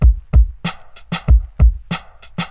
短暂的旧学校Banger 节拍95 BPM
Tag: 95 bpm Hip Hop Loops Drum Loops 435.37 KB wav Key : Unknown